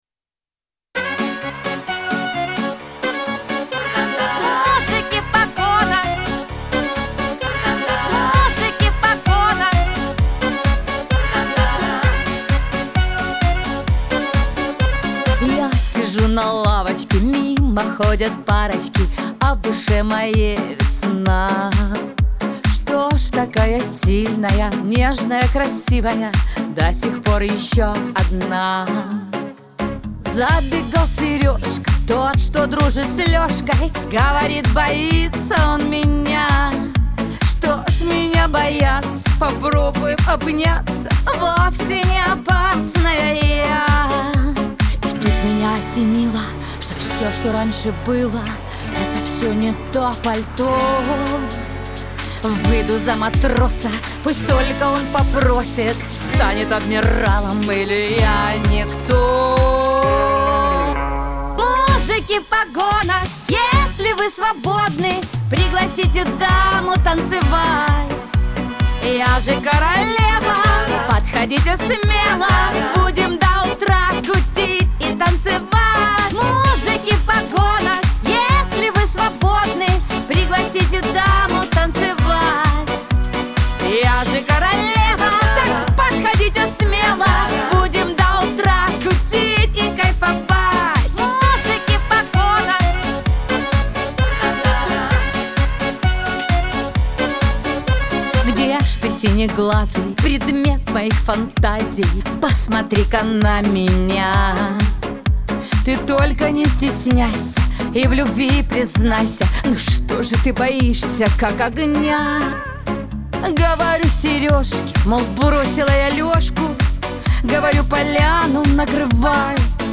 эстрада
диско
dance , pop